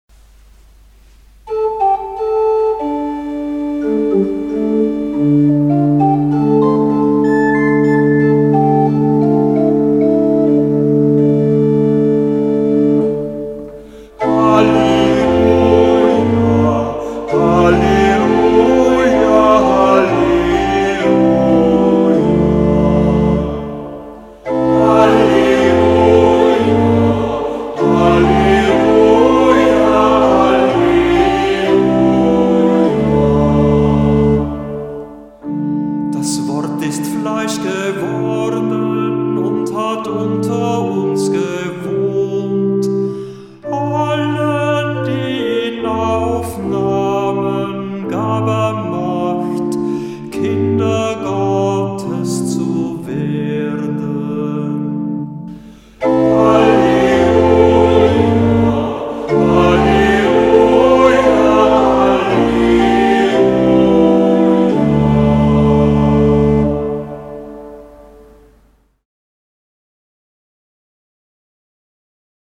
Hörbeispiele aus dem Halleluja-Büchlein
Halleluja im Gotteslob